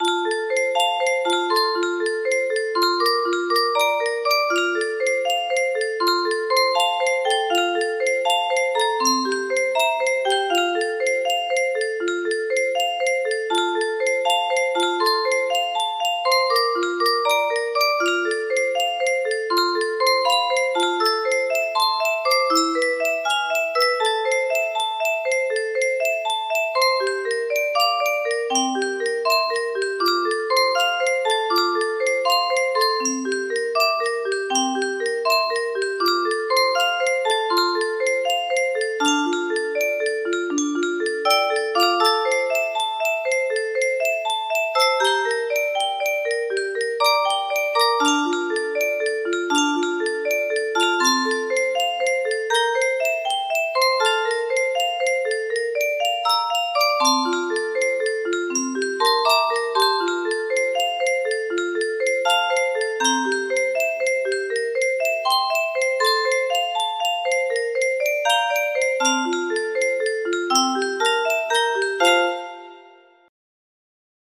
O holy night music box melody
Grand Illusions 30 (F scale)